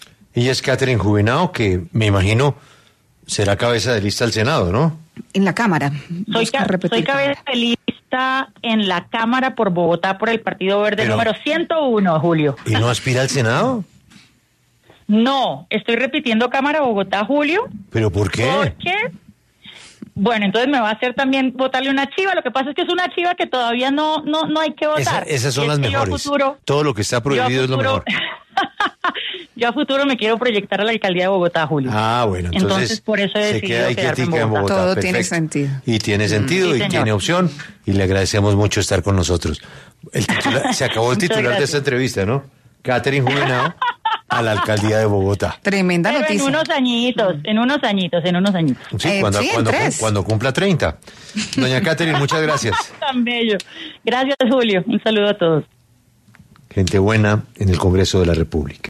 En diálogo con 6AM W, de Caracol Radio con Julio Sánchez Cristo, la representante a la Cámara, Catherine Juvinao, confirmó en primicia que buscará a futuro aspirar a la Alcaldía de Bogotá.